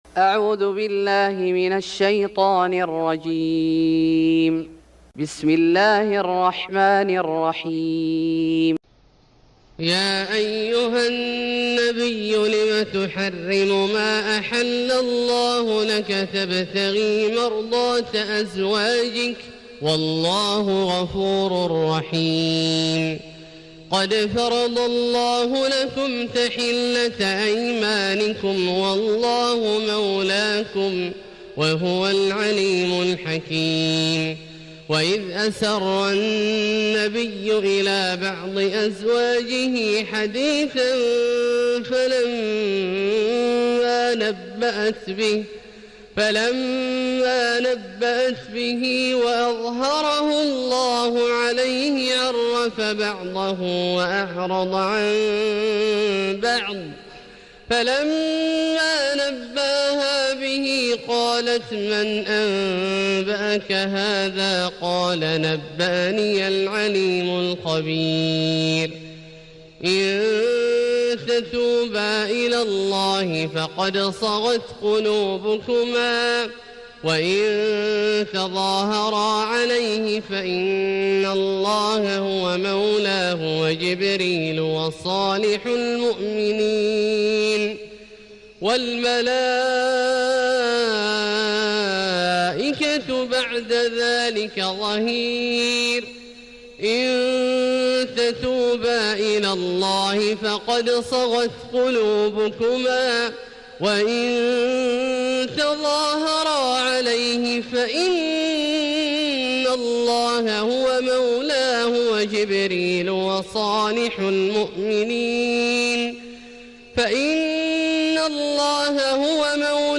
سورة التحريم Surat At-Tahrim > مصحف الشيخ عبدالله الجهني من الحرم المكي > المصحف - تلاوات الحرمين